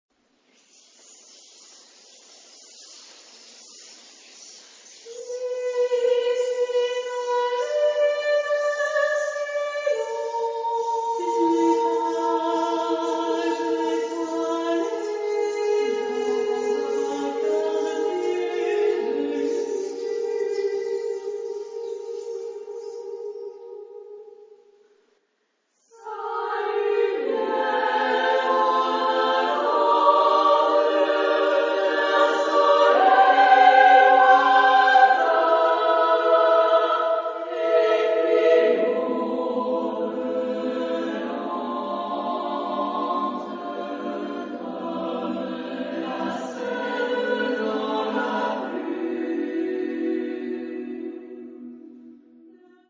Genre-Style-Forme : Profane ; Poème ; contemporain
Type de choeur : SSAA  (4 voix égales OU égales de femmes )
Tonalité : polymodal